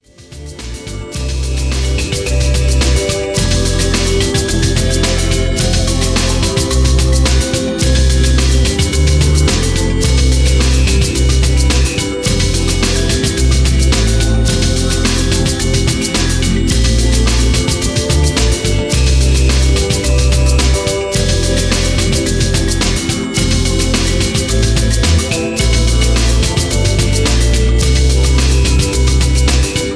Upbeat pop style music.
Royalty Free Music for use in any type of